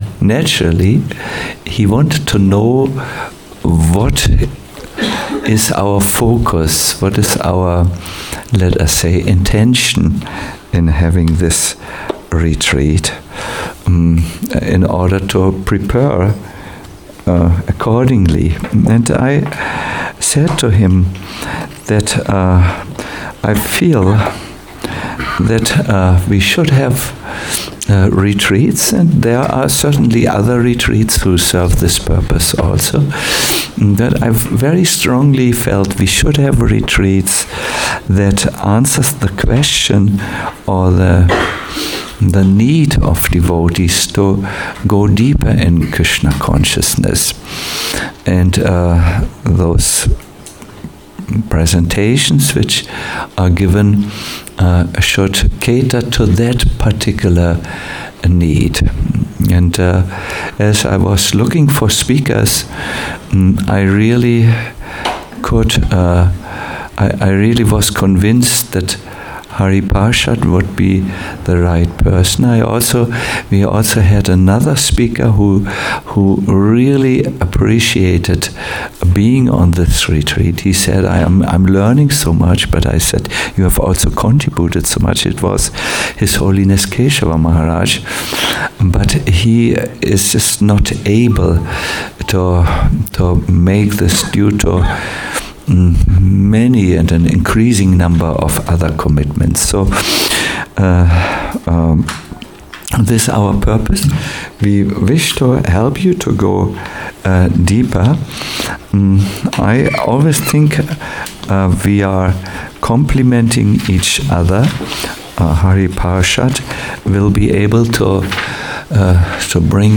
Bhakti Immersion 1 - Entering the retreat - a lecture